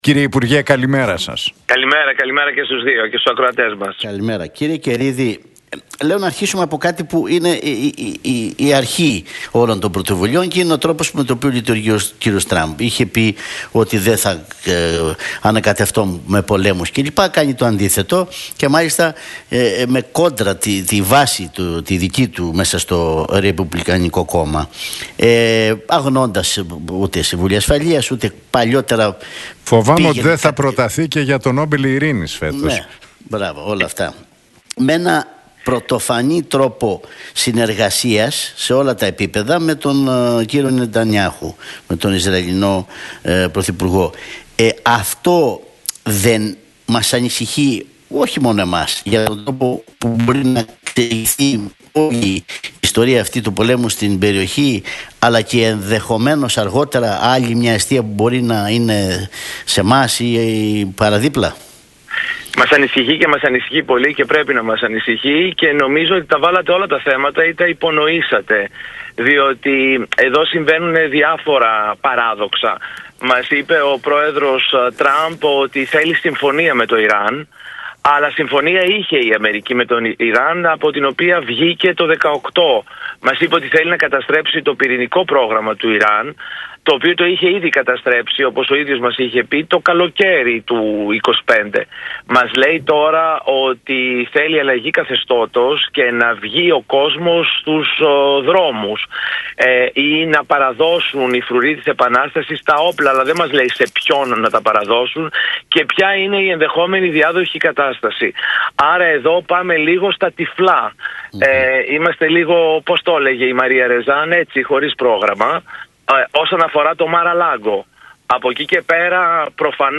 Καιρίδης στον Realfm 97,8: Δεν πιστεύω ότι η χώρα απειλείται αλλά θα υποστούμε συνέπειες από την άνοδο του πετρελαίου